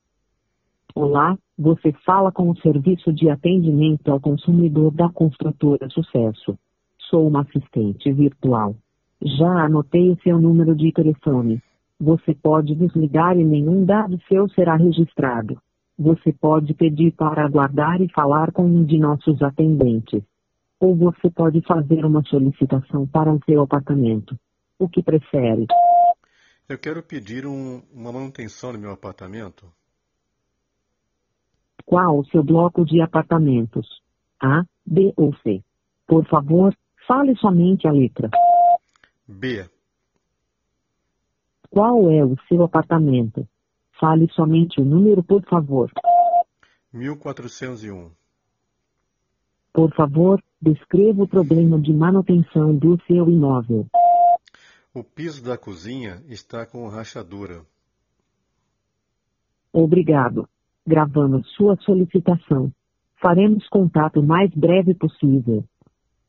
Ligação para SAC